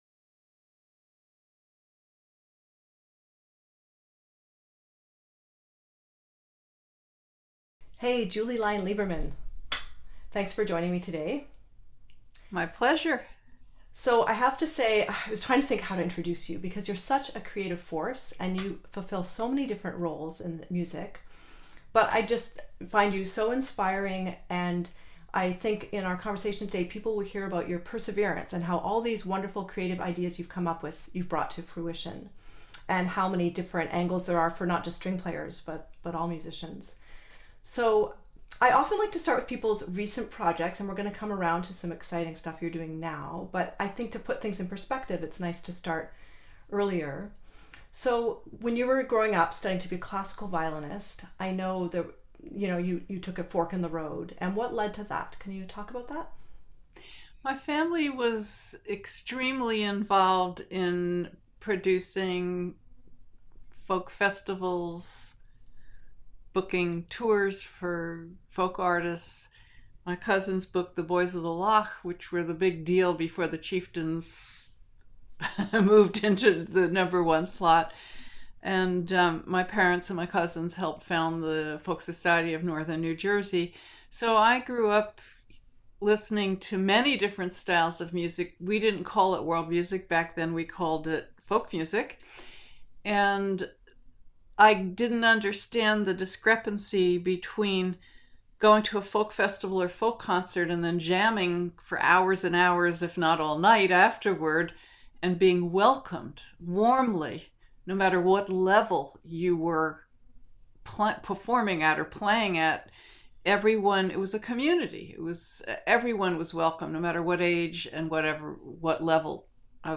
This is a re-release with improved audio.
In this conversation we discuss her unique path through world music, some of the research she’s done for her book and radio programs, illuminating the history of slave fiddlers and the evolution of improvised string playing in the United States. She gives some wonderful advice about following your own path, and at the end, she plays two tunes in different styles.